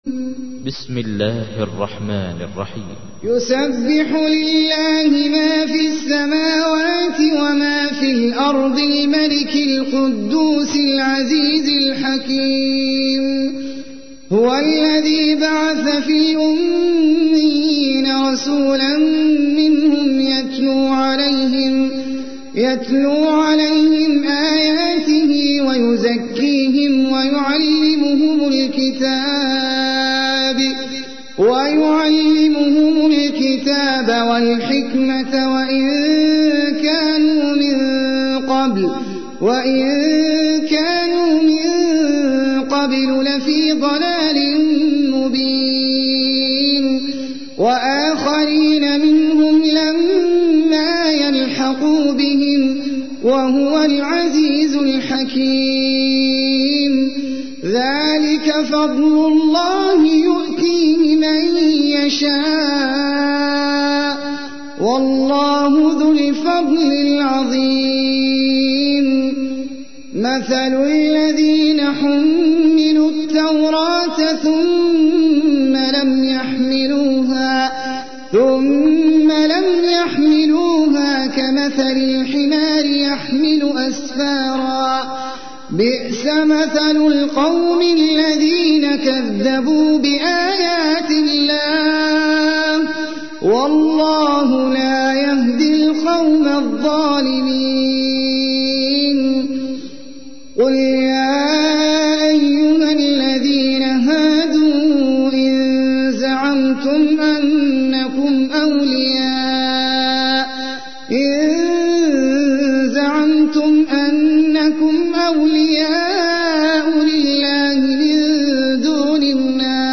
تحميل : 62. سورة الجمعة / القارئ احمد العجمي / القرآن الكريم / موقع يا حسين